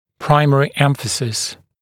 [‘praɪmərɪ ’emfəsɪs][‘праймэри ‘эмфэсис]основное значение, первоочередное внимание